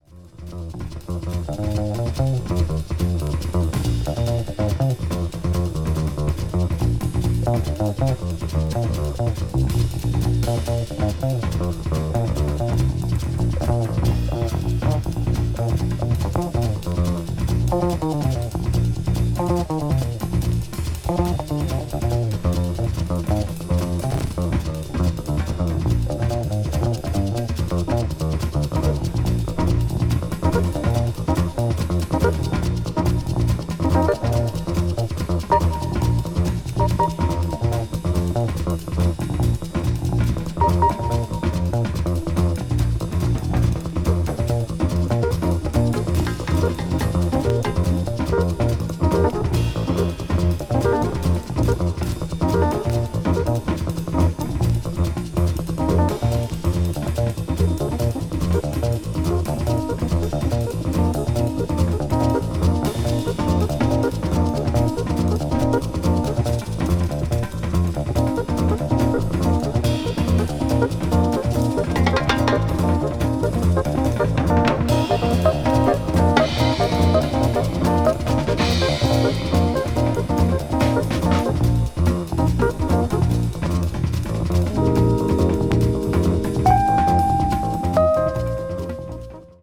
acoustic piano
electric piano
contemporary jazz   crossover   fusion   spritual jazz